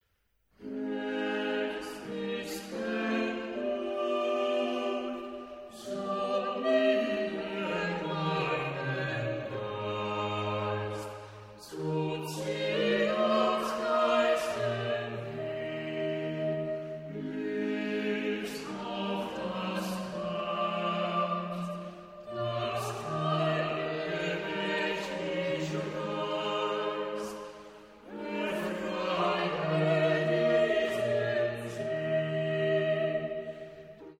Voorbeeld 17 daarentegen laat zien dat een dissonant interval tussen twee hoekpunten een buitengewoon dramatisch effect kan hebben: